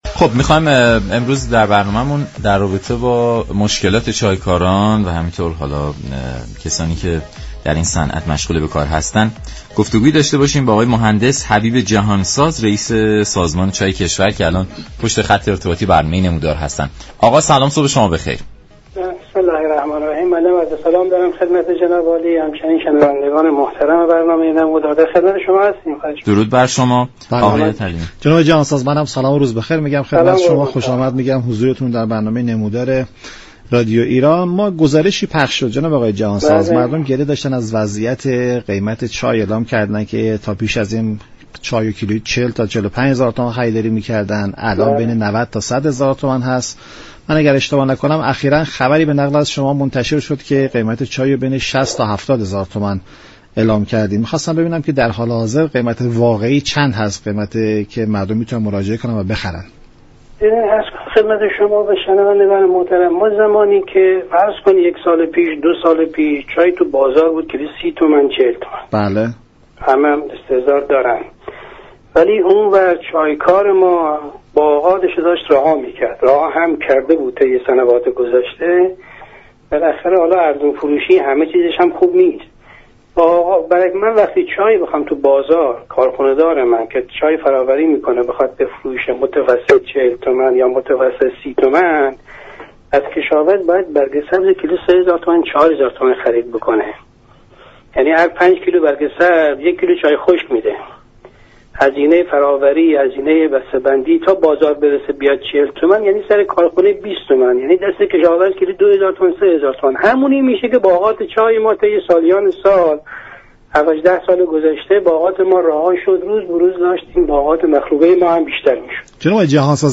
به گزارش شبكه رادیویی ایران، حبیب جهان ساز رییس سازمان چای كشور در برنامه نمودار به وضعیت بازار چای كشور اشاره كرد و گفت: ارزانی قیمت چای طی سال های گذشته به تولید كننده و كشاورز آسیب زیادی وارد كرده است تا جایی كه این ارزانی قیمت باعث رهایی بسیاری از باغات چای شد.
برنامه نمودار شنبه تا چهارشنبه هر هفته ساعت 10:20 از رادیو ایران پخش می شود.